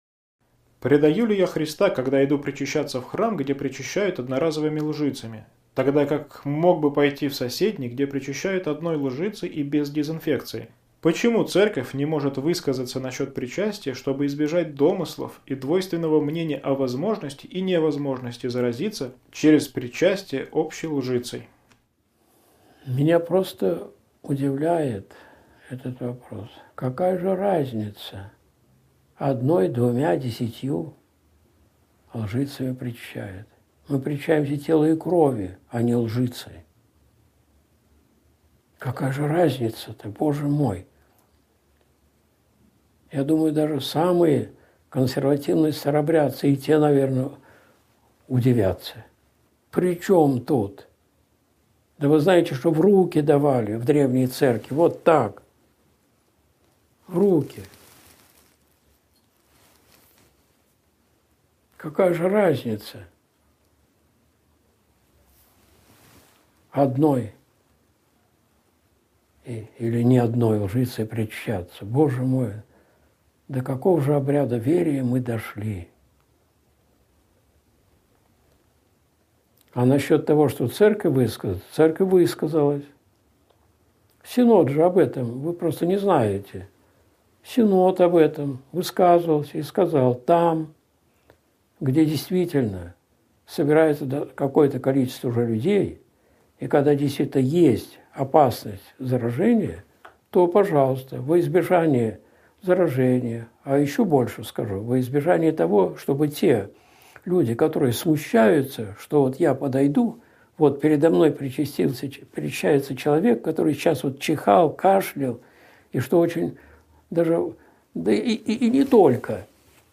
Ответы на вопросы (Часть 3) (Прямой эфир, 11.12.2020)